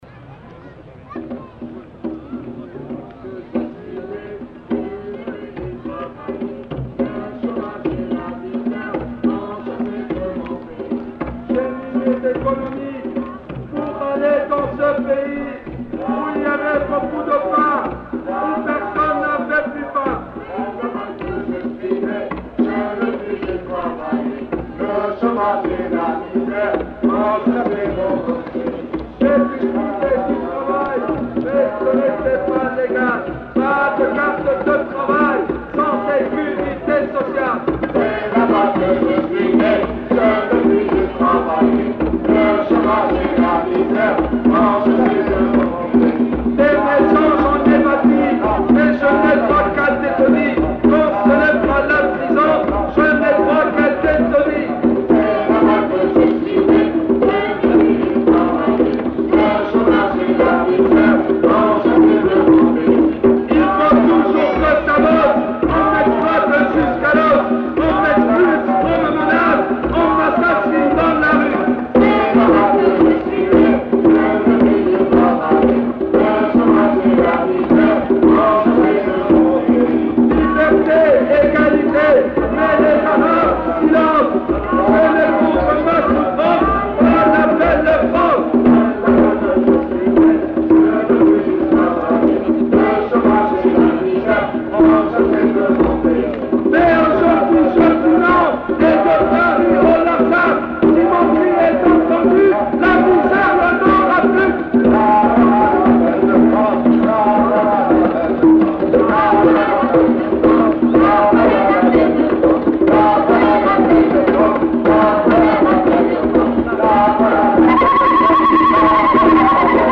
Chant (non identifié)
Lieu : [sans lieu] ; Aveyron
Genre : chanson-musique
Type de voix : voix mixtes
Production du son : chanté
Instrument de musique : percussions
Notes consultables : Incipit inaudible.